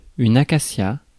Ääntäminen
Synonyymit robinier faux-acacia Ääntäminen France: IPA: [akasja] Haettu sana löytyi näillä lähdekielillä: ranska Käännös Substantiivit 1. акация Suku: f .